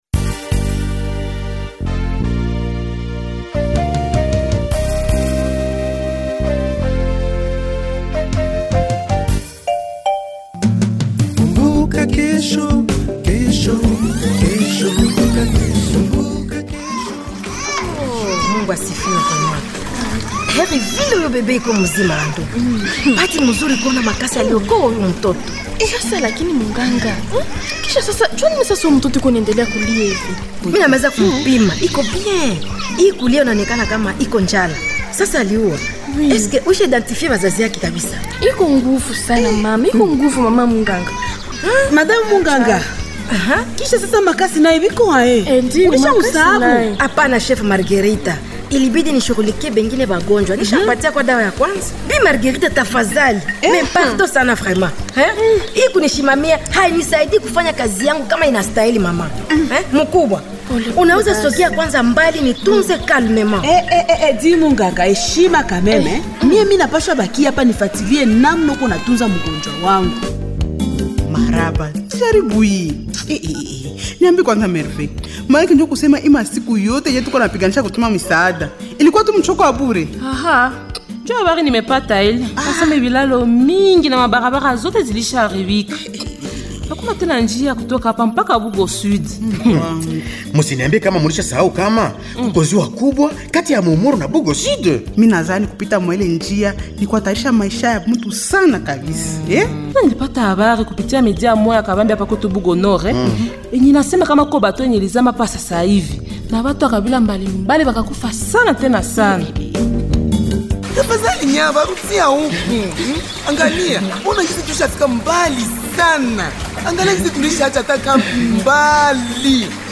Ce feuilleton Kumbuka Kesho est à suivre sur toutes les radios partenaires de Benevolencija.